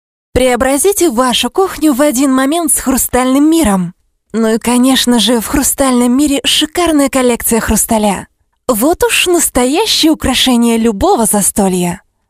Sprecherin russisch ukrainisch.
Sprechprobe: Sonstiges (Muttersprache):